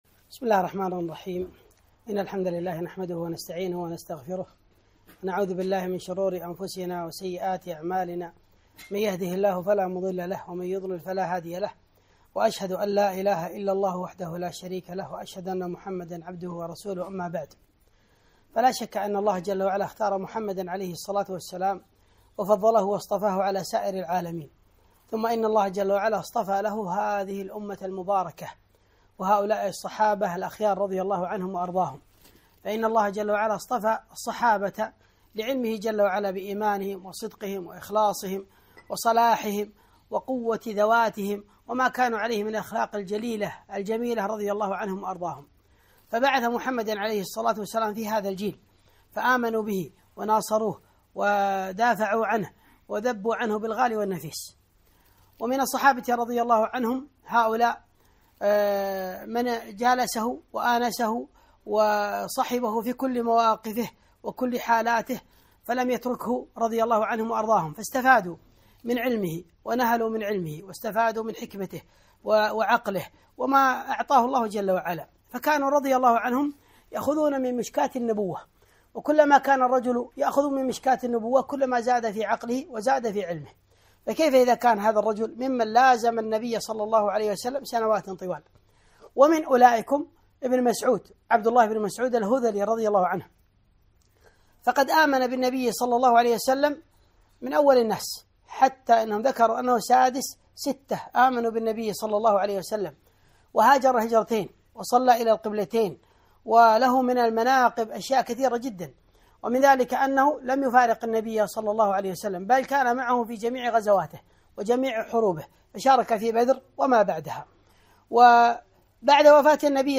محاضرة - حِكم ابن مسعود رضي الله عنه